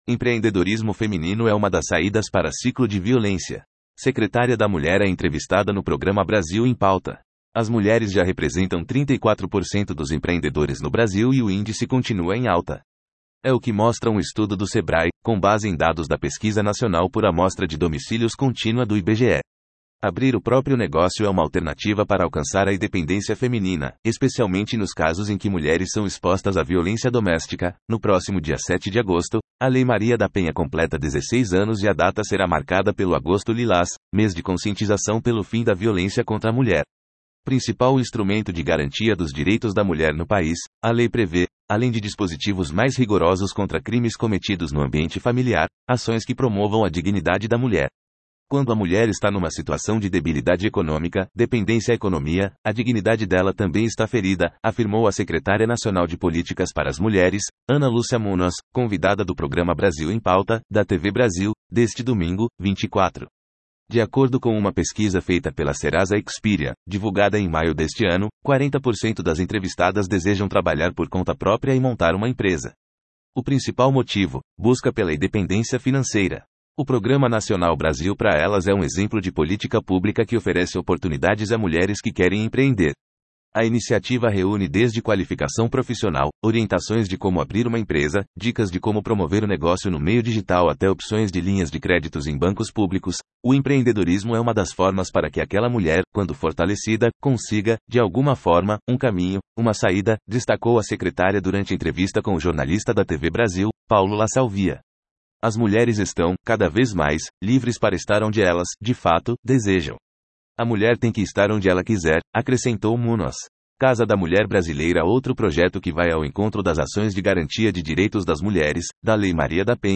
A secretária nacional de Políticas para as Mulheres/MMFDH, Ana Muñoz. é a entrevistada do programa, Brasil em Pauta, na TV Brasil
Secretária da Mulher é entrevistada no programa Brasil em Pauta